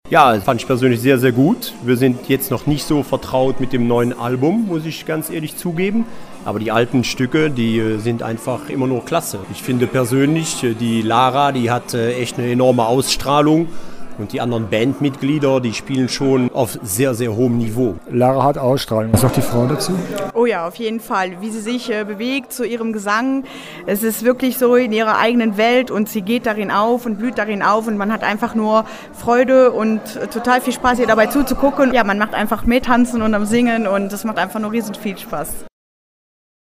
…und die Konzertbesucher waren begeistert: